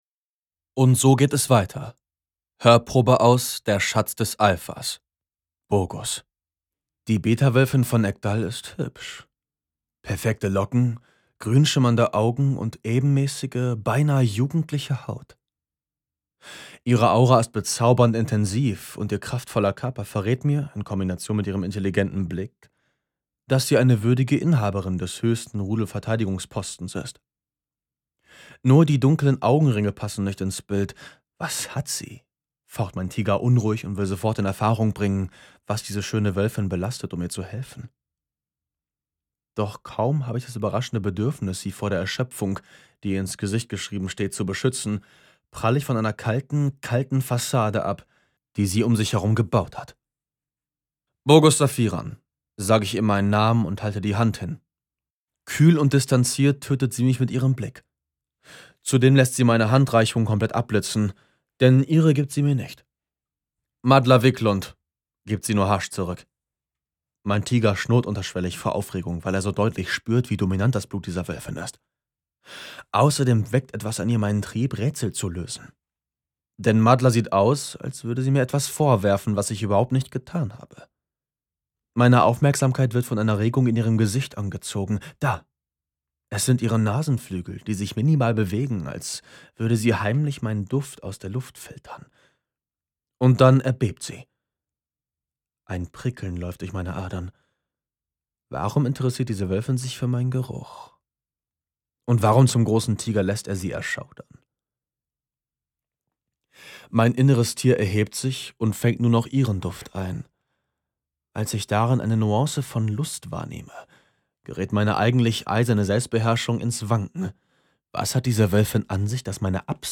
sehr variabel, hell, fein, zart
Jung (18-30)
Hörbuch Fantasy
Audiobook (Hörbuch)